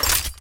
EquipOff.wav